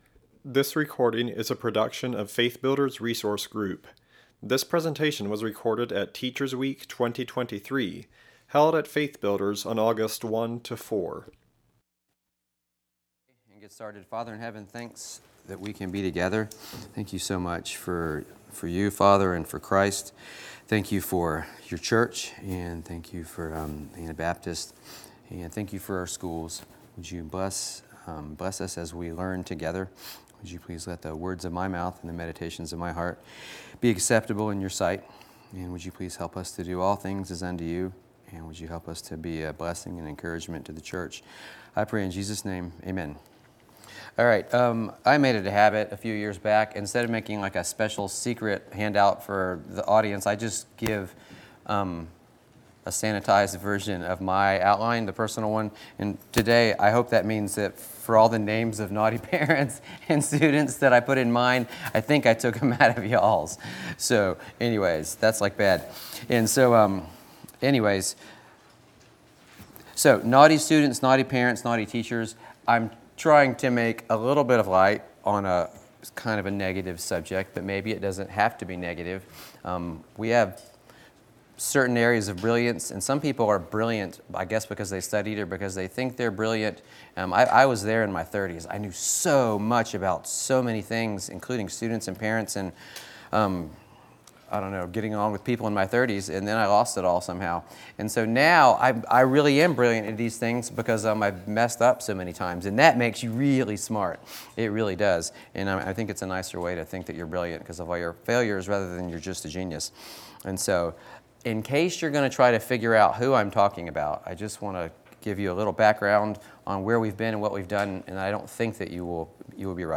Home » Lectures » Naughty Students, Naughty Parents, and Naughty Teachers!